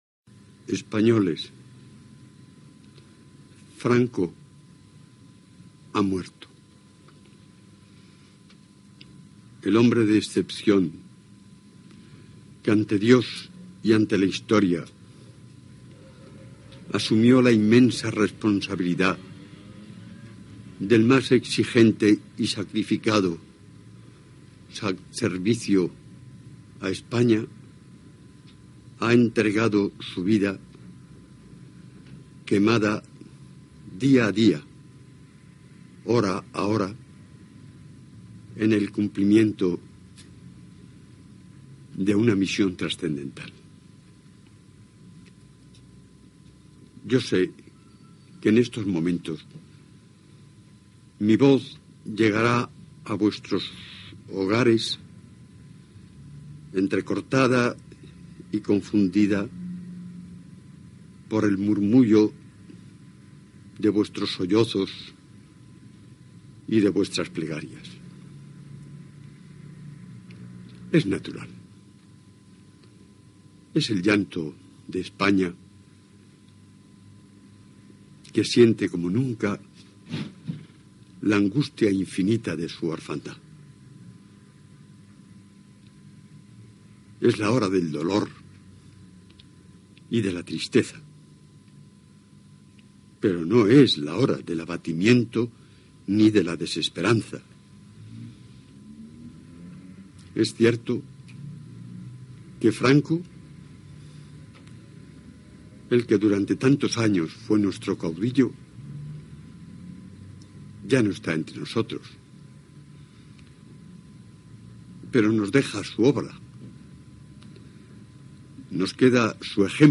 El president del govern espanyol Carlos Arias Navarro comunica la mort del cap d'Estat Francisco Franco i llegeix el missatge de comiat que va deixar escrit "el caudillo" i on expressava el seu testament polític
Informatiu